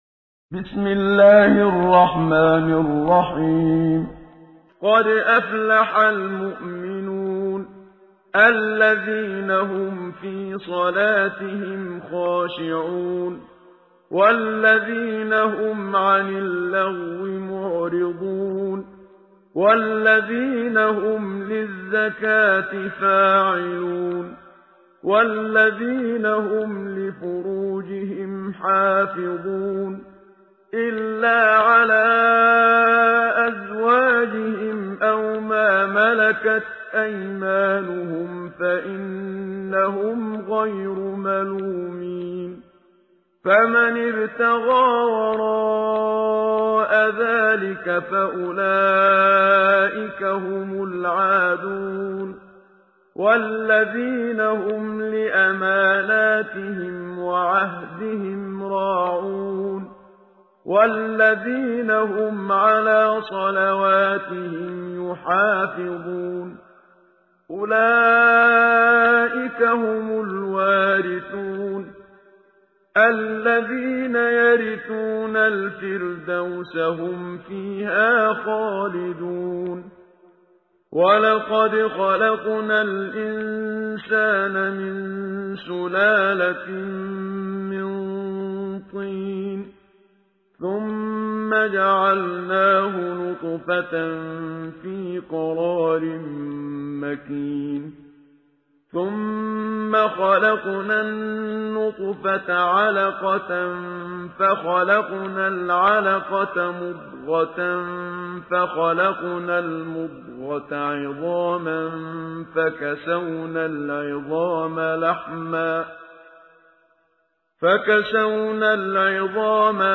سورة المؤمنون ... جاهزة للتحميل بصوت خاشع